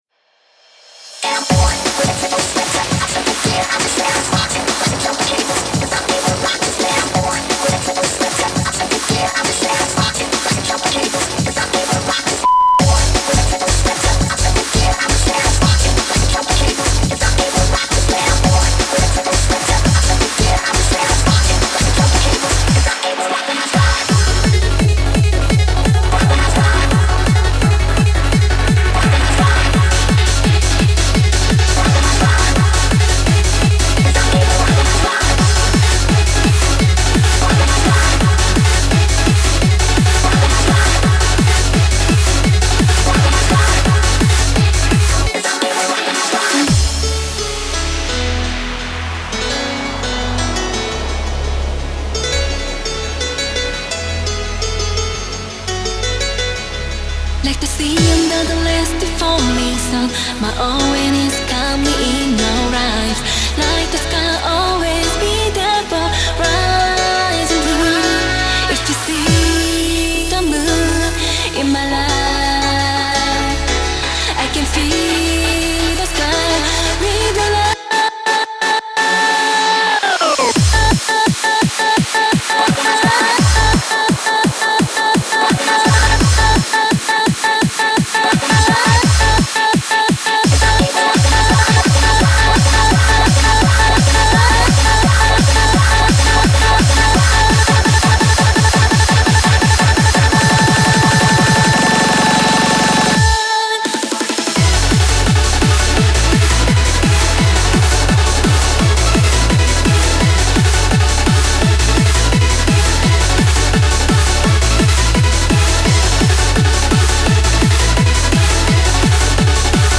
BPM85-170
Audio QualityPerfect (High Quality)
[J-Core]